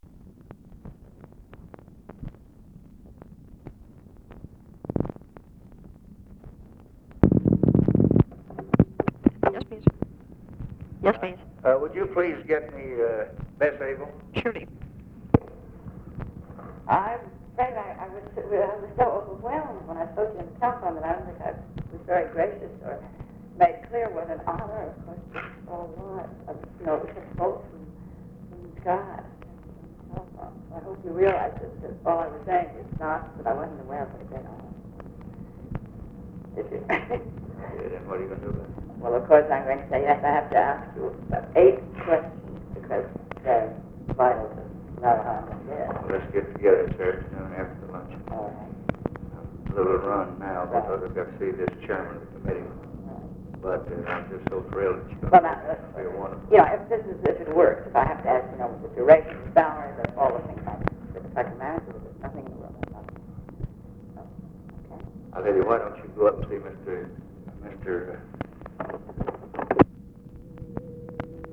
Conversation with OFFICE CONVERSATION
Secret White House Tapes | Lyndon B. Johnson Presidency